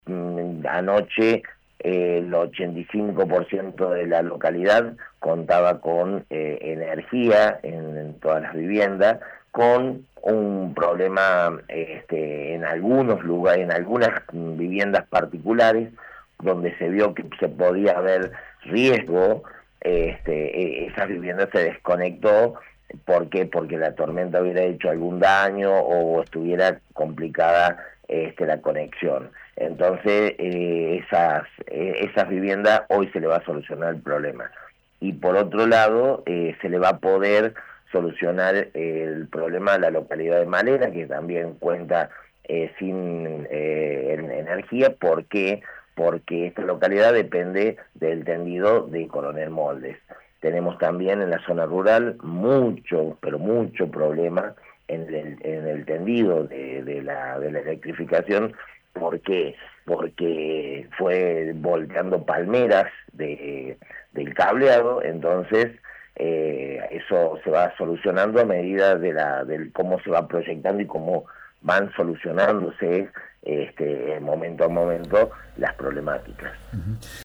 El intendente Ezequiel Moiso comentó detalles de las pérdidas producidas en la localidad.